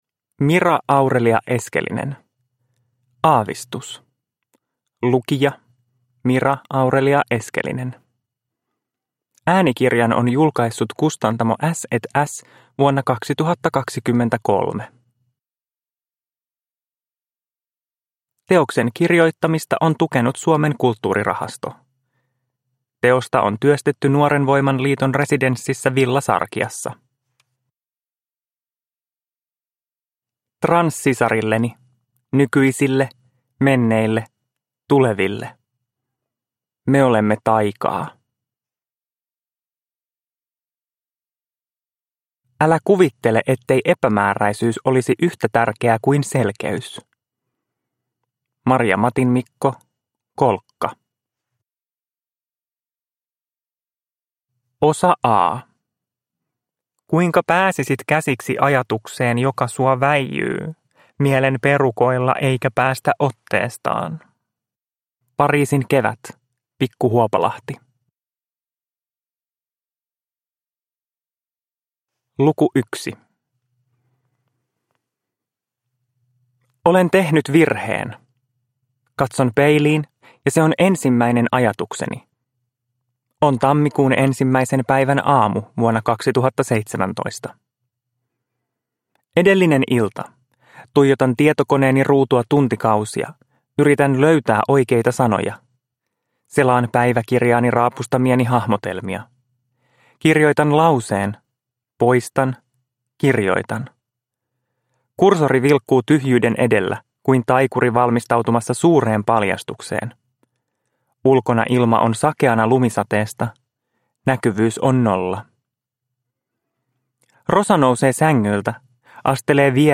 Aavistus – Ljudbok – Laddas ner